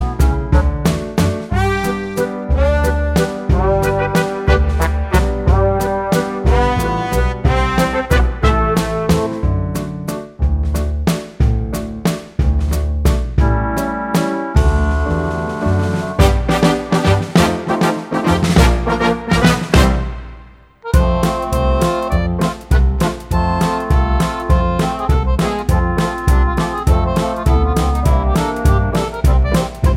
No Backing Vocals Oldies (Female) 3:56 Buy £1.50